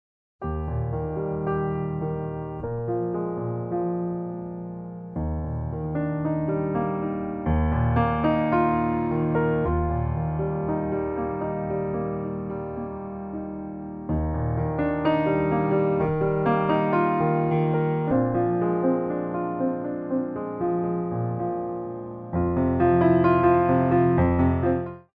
By Pianist & Ballet Accompanist
Slow Tendu